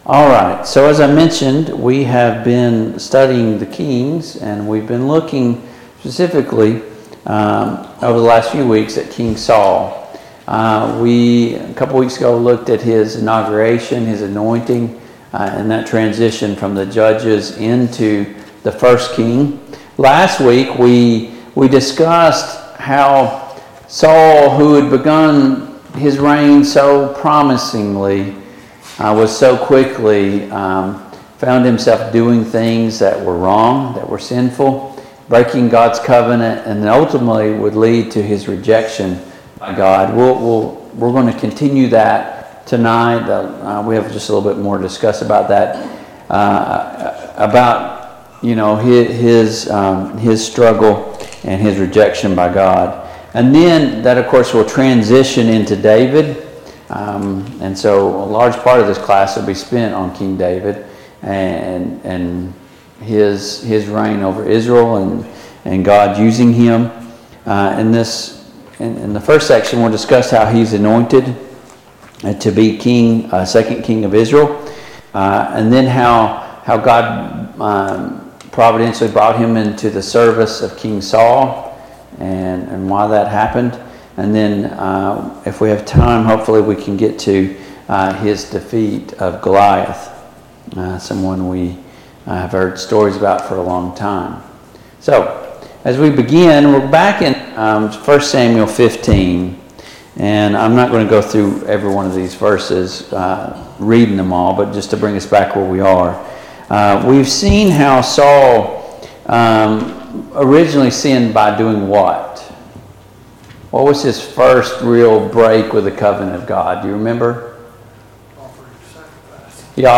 Passage: I Samuel 15, I Samuel 16 Service Type: Mid-Week Bible Study